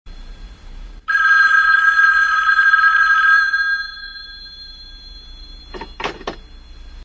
OLD TELEPHONE RINGING AND PICK UP.mp3
.WAV .MP3 .OGG 0:00 / 0:07 Type Mp3 Duration 0:07 Size 1,19 MB Samplerate 44100 Hz Bitrate 256 kbps Channels Stereo Old Rotary telephone ringing in my grandmother's kitchen.
old_telephone_ringing_and_pick_up_1lo.ogg